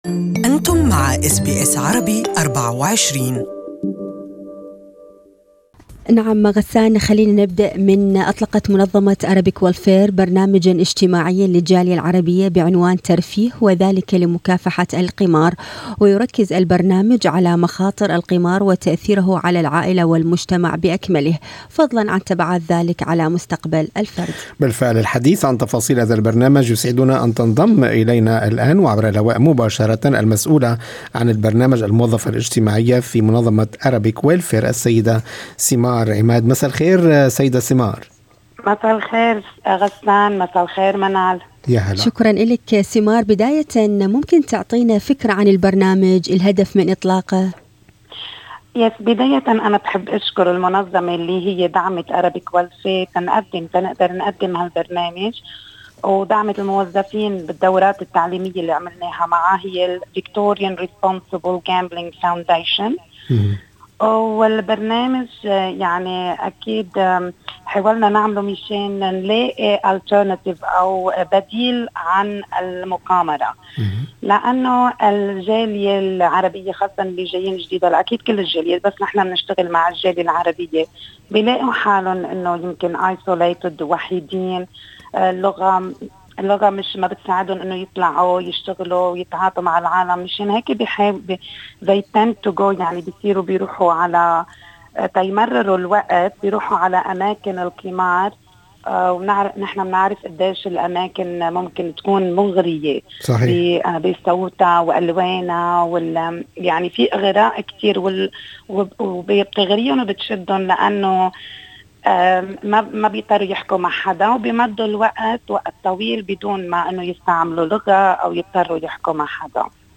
This interview is only available in Arabic.